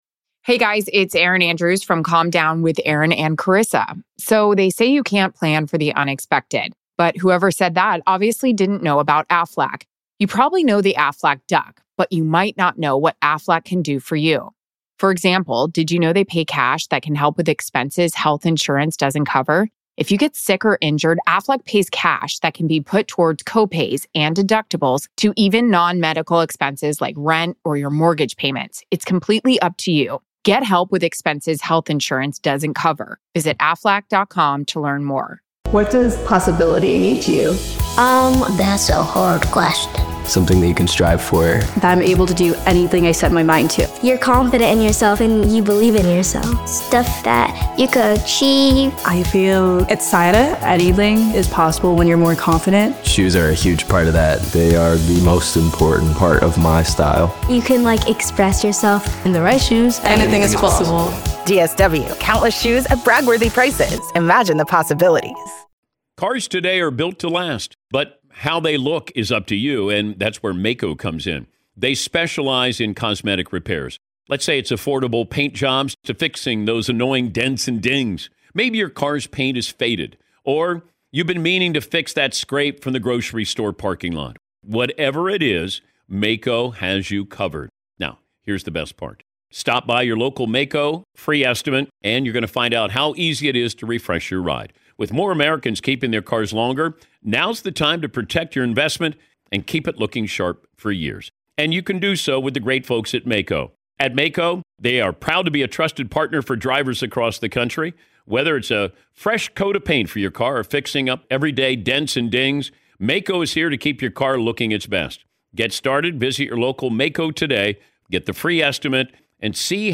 Attempting to poke holes in Aperture’s crash analysis and methodology, Read’s attorneys challenge assumptions, question visibility models, and raise issues of expert bias. This is the first round of a highly technical — and tactical — courtroom exchange.